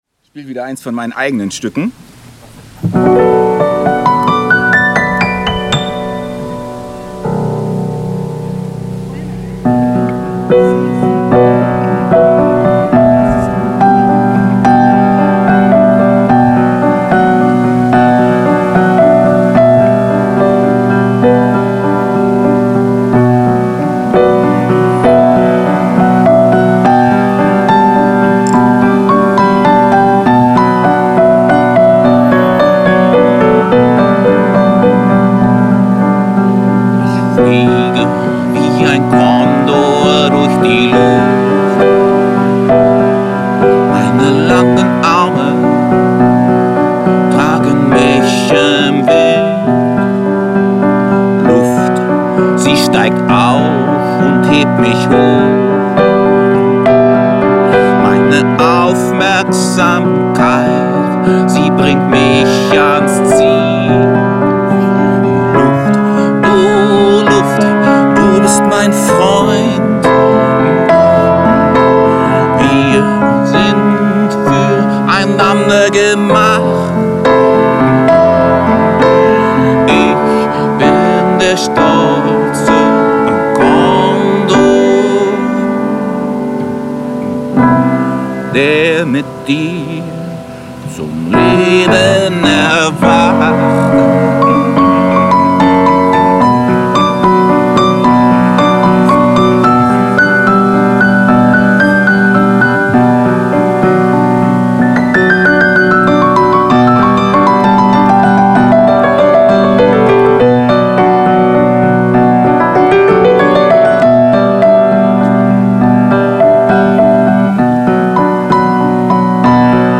Waldpflanzengarten: